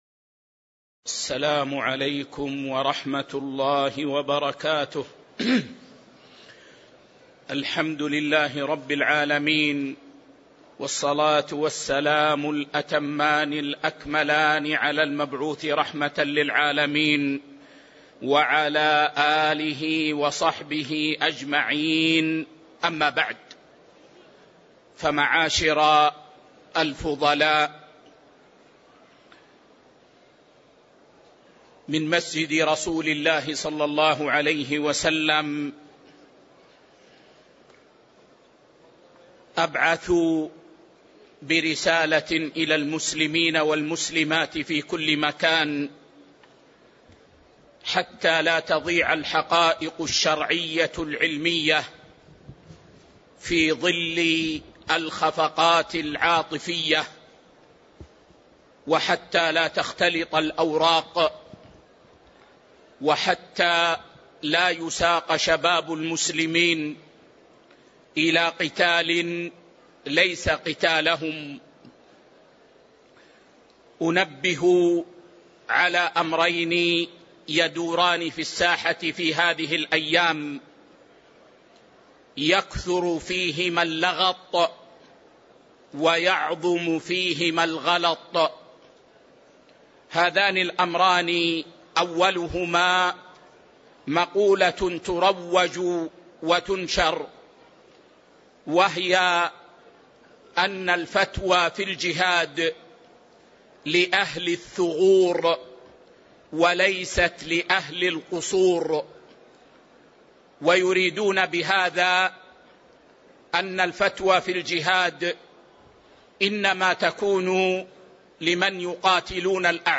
شرح دليل الطالب لنيل المطالب الدرس 329 كتاب الفرائض -[20] باب ميراث المعتق بعضه